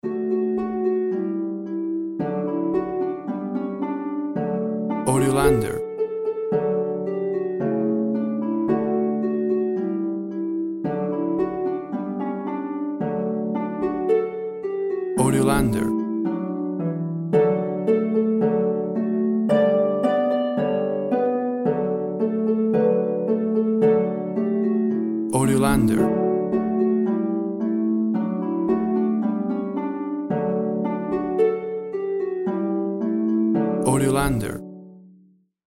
WAV Sample Rate 16-Bit Stereo, 44.1 kHz
Tempo (BPM) 110